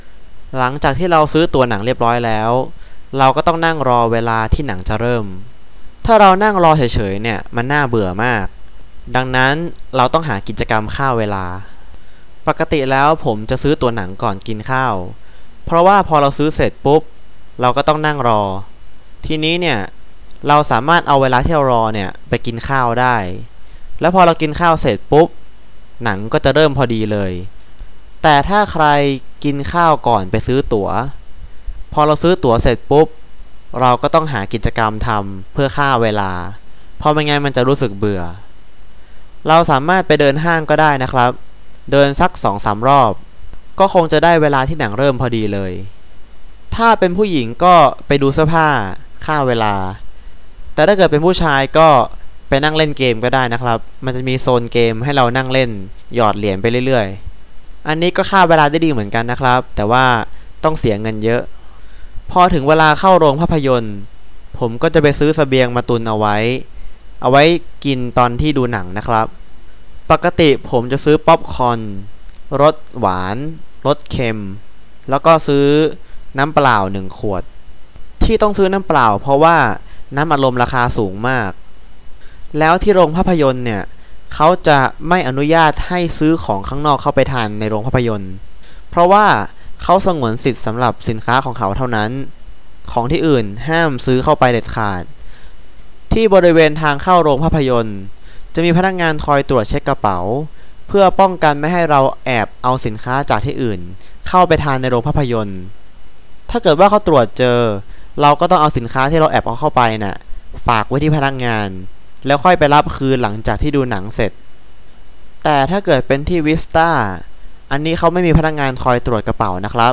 All recordings are unscripted, natural speech and 100% in Thai; they all come with a transcript.
Native speakers